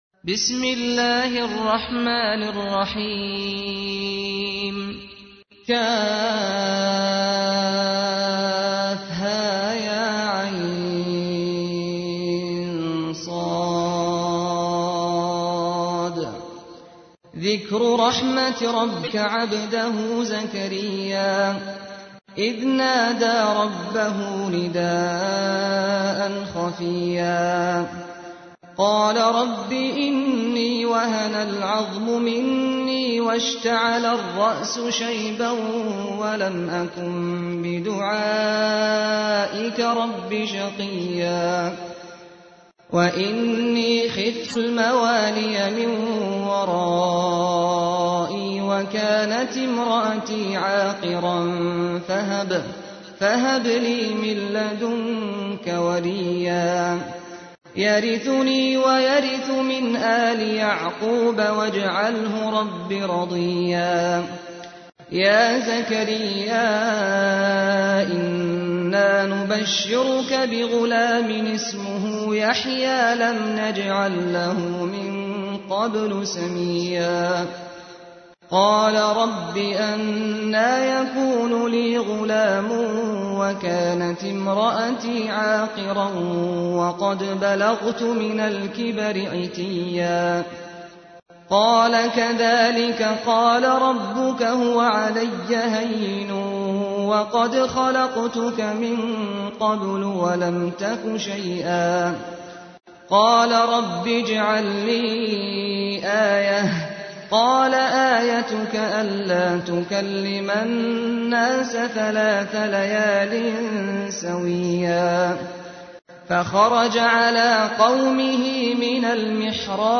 تحميل : 19. سورة مريم / القارئ سعد الغامدي / القرآن الكريم / موقع يا حسين